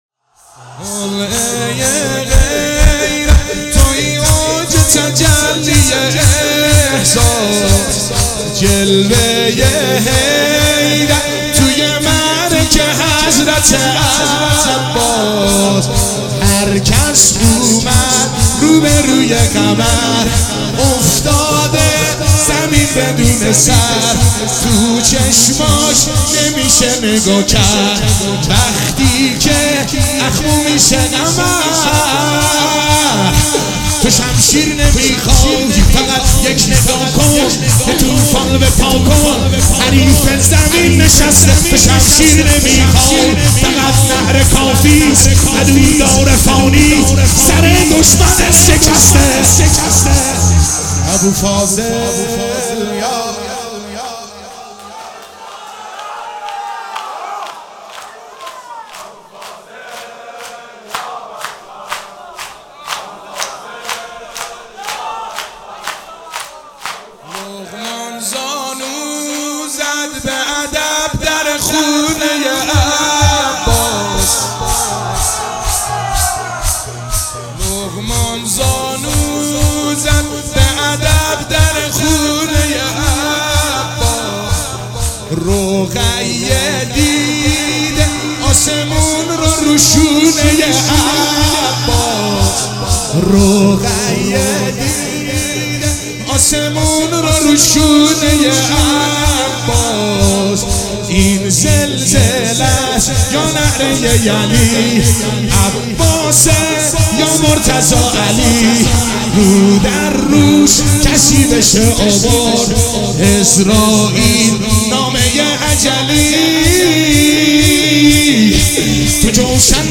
محفل دیوانگان حضرت اباالفضل مشهد شور